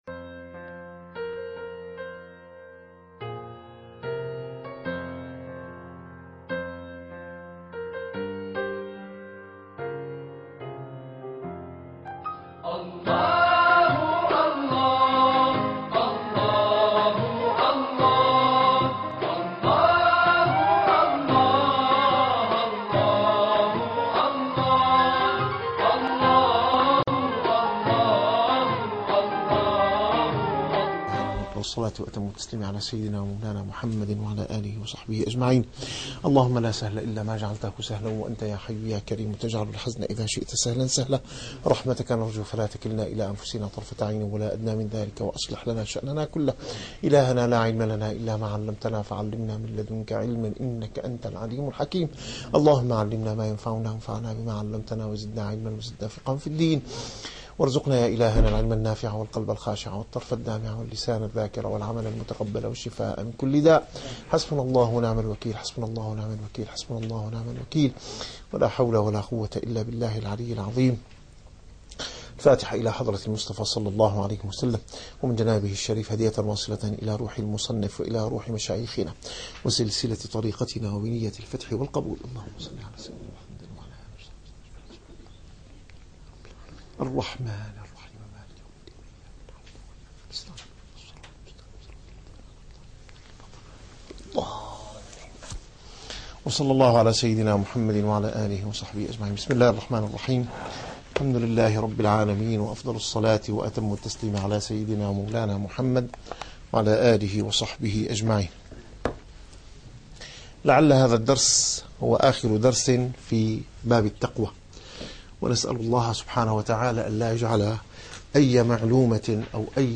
- الدروس العلمية - الرسالة القشيرية - الرسالة القشيرية / الدرس الخامس والخمسون.